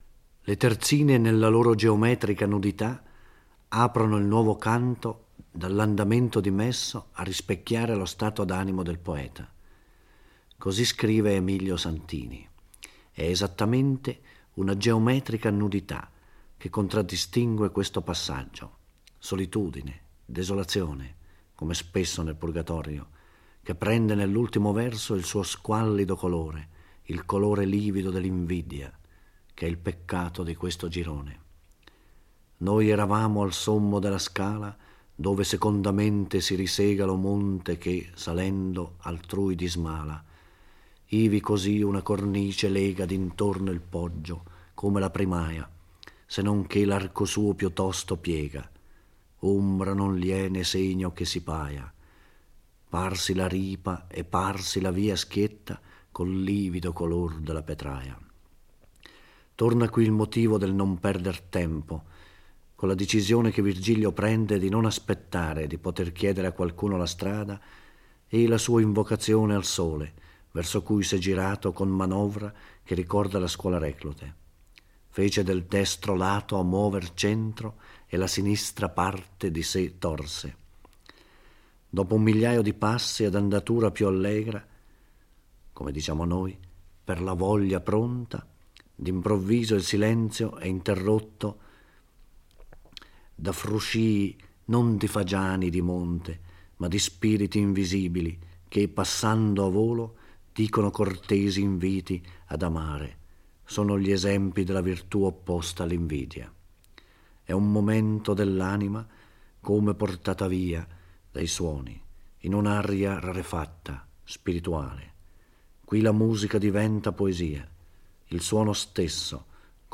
Giorgio Orelli legge e commenta il XIII canto del Purgatorio. Dante e Virgilio giungono alla seconda cornice, dove all'inizio non sembra esserci segno di anime penitenti.